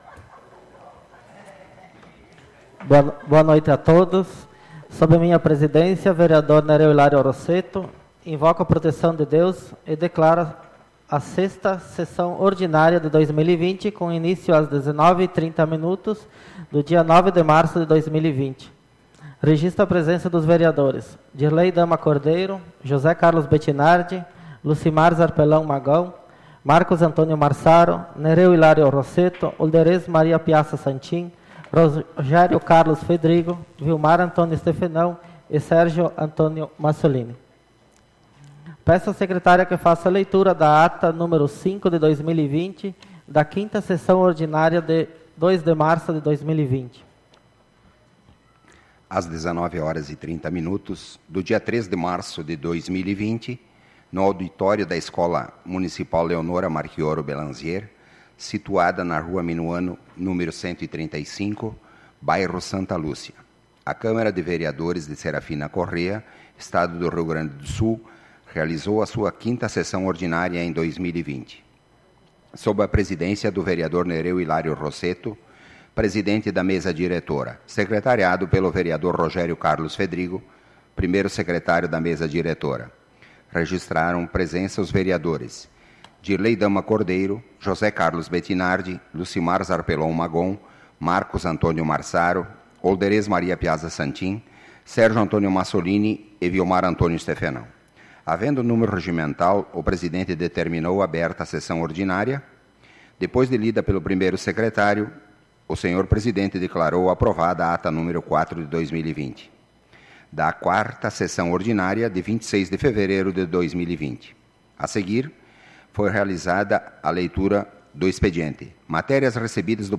SAPL - Câmara de Vereadores de Serafina Corrêa - RS
Tipo de Sessão: Ordinária